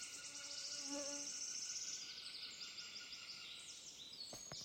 Birds -> Birds of prey ->
Eurasian Sparrowhawk, Accipiter nisus
StatusAgitated behaviour or anxiety calls from adults